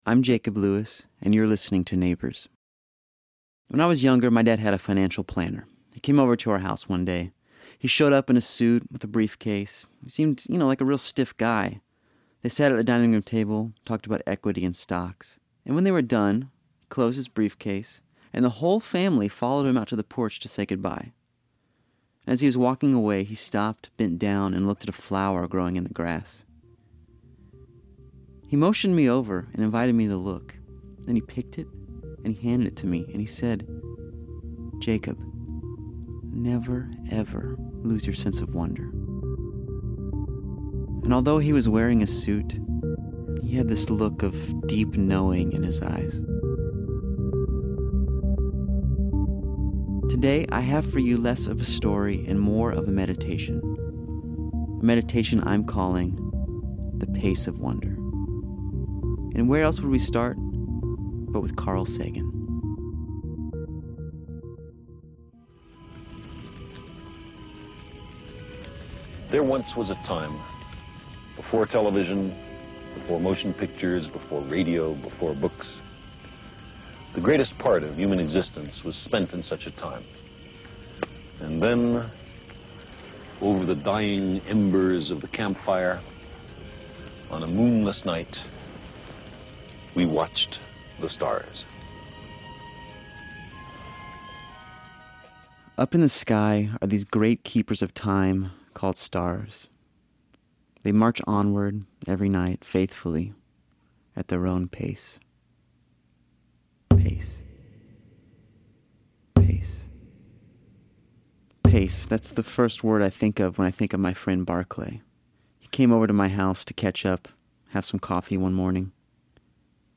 This episode is more of a meditation than a story.